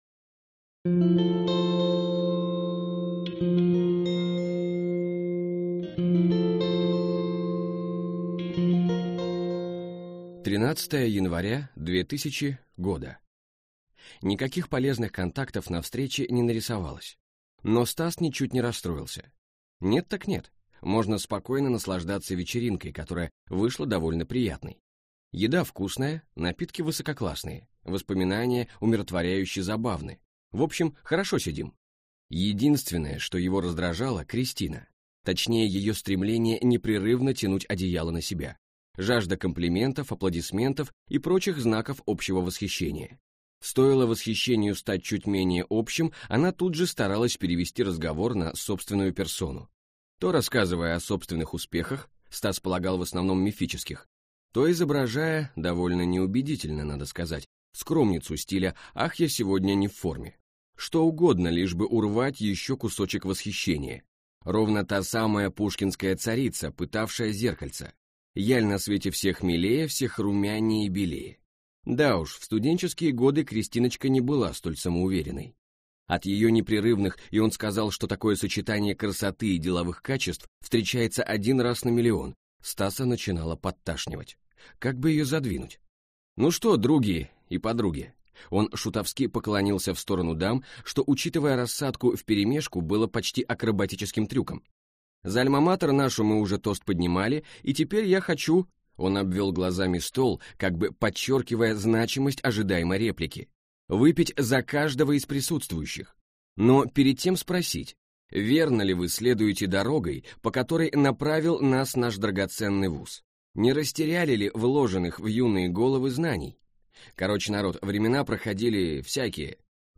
Аудиокнига Маскарад на семь персон | Библиотека аудиокниг